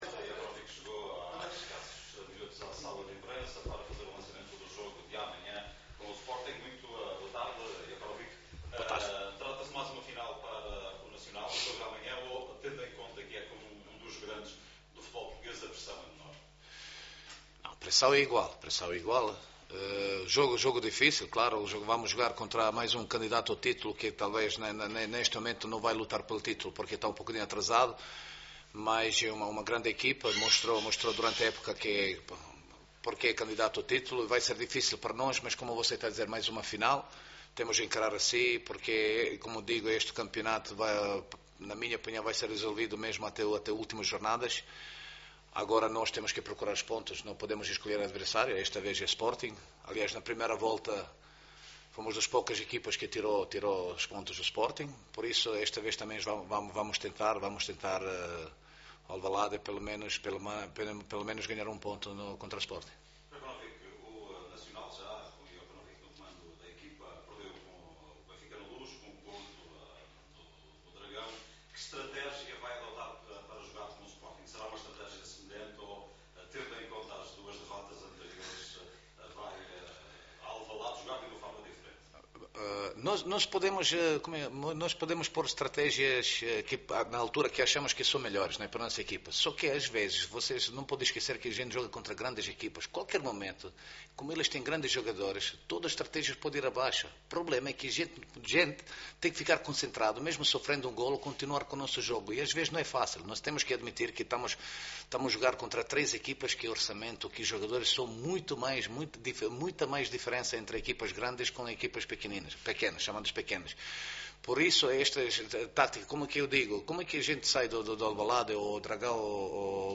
Na conferência de imprensa de antevisão o jogo, o treinador do Nacional, Predrag Jokanovic, admitiu que não será fácil o jogo para a sua equipa mas assumiu o desejo de somar pelo menos um ponto.